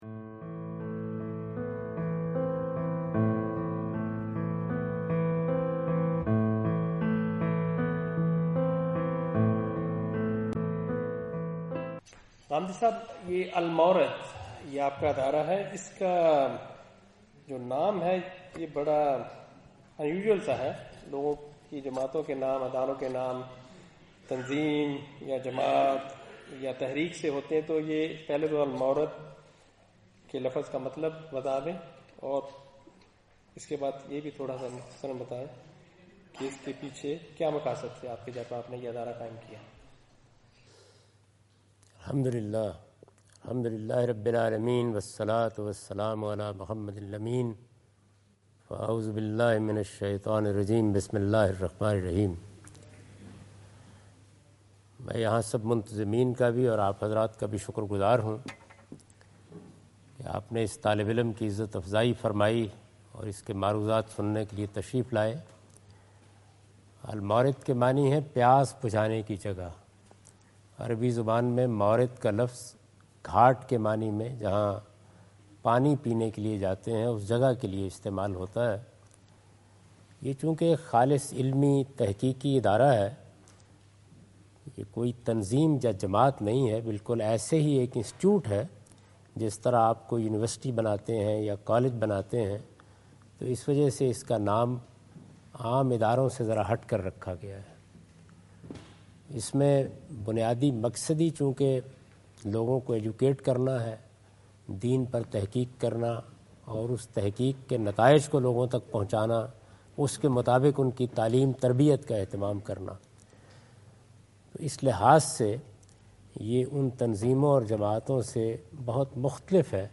An Interactive Session with Javed Ahmad Ghamidi (Canberra 3rd October 15)
Javed Ahmad Ghamid answering the questions of audience in Canberra Australia on 03rd October 2015.